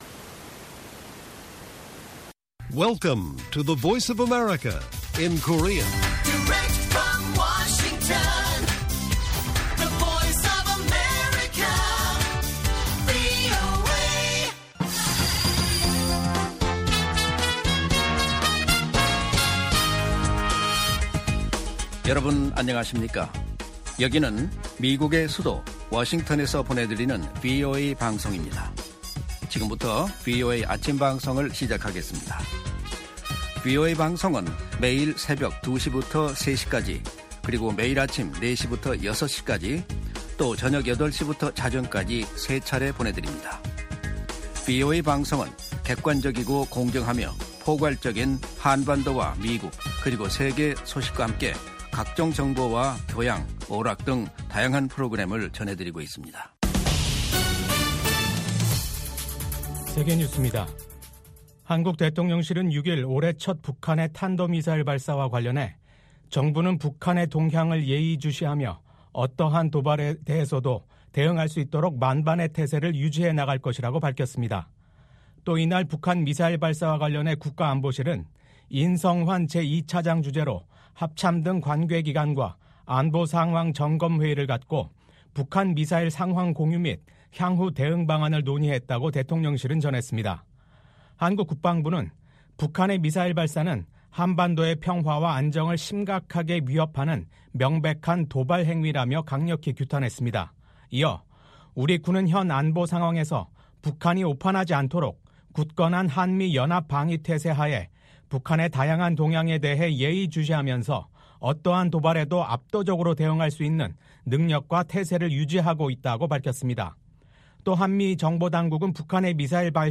생방송 여기는 워싱턴입니다 2025/1/7 아침